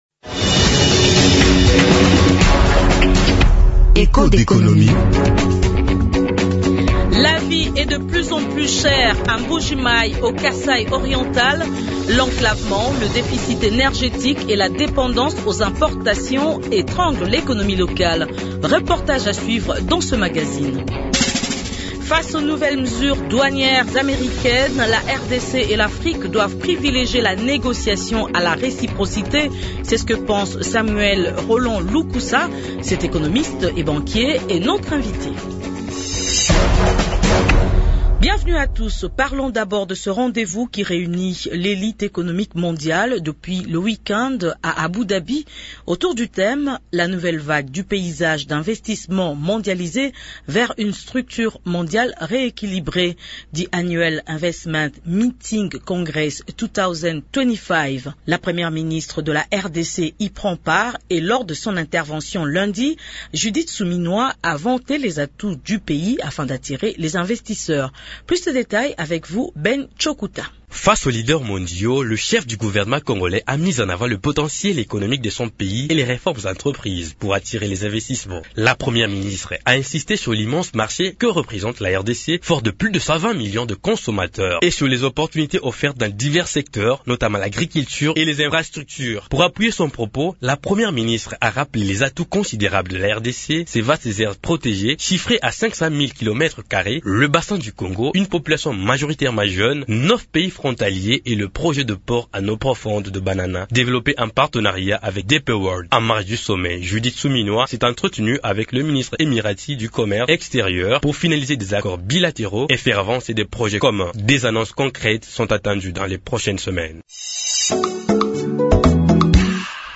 La vie devient de plus en plus chère à Mbuji-Mayi au Kasaï Oriental. L’enclavement, le déficit énergétique et la dépendance aux importations étranglent l’économie locale. Reportage à suivre dans ce numéro du magazine Echos d’économie.